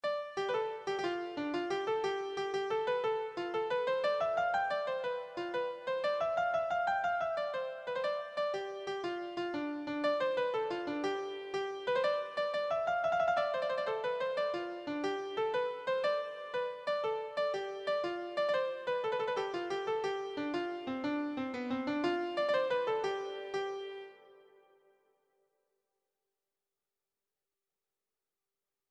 Free Sheet music for Keyboard (Melody and Chords)
6/8 (View more 6/8 Music)
C major (Sounding Pitch) (View more C major Music for Keyboard )
Keyboard  (View more Easy Keyboard Music)
Traditional (View more Traditional Keyboard Music)